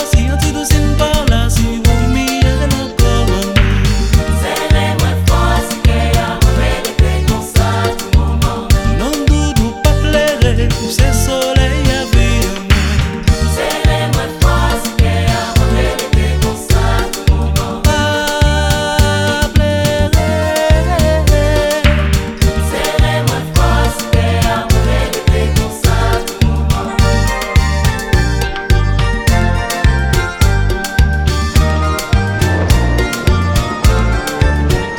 # Cajun